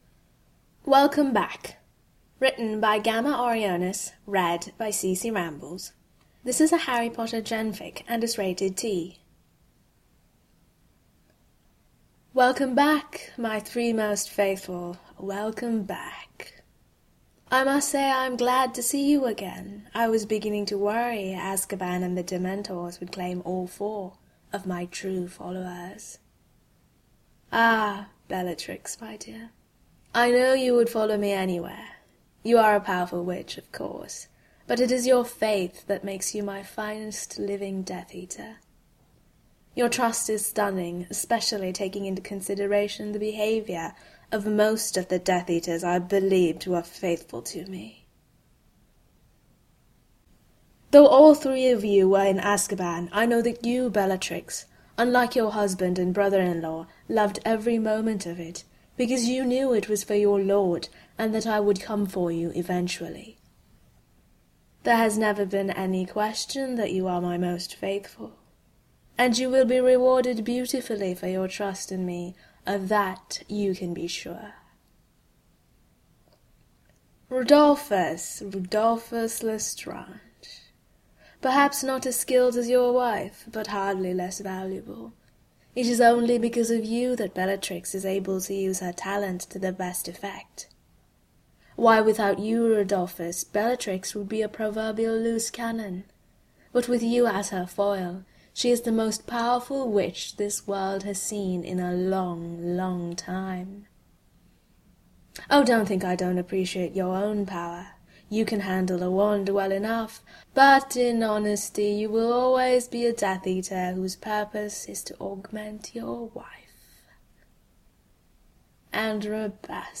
character: lord voldemort, type: podfic, genre: gen, !worshipdarklord2013